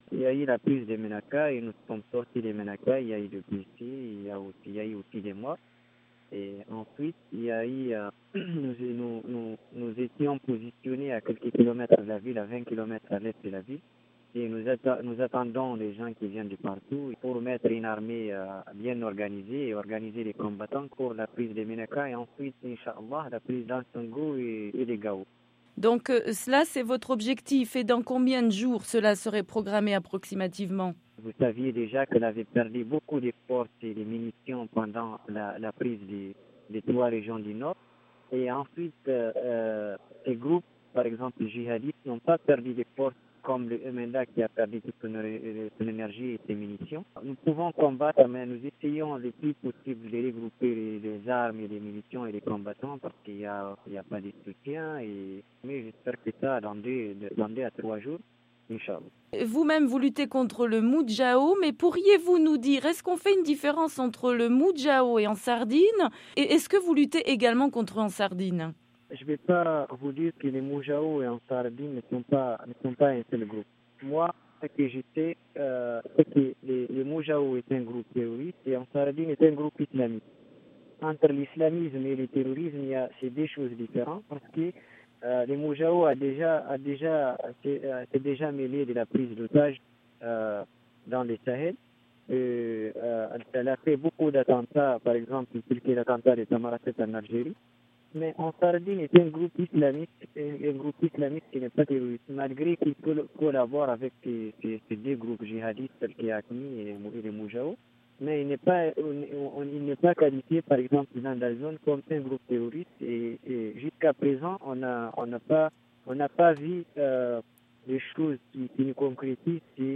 un responsable de la branche armée du MNLA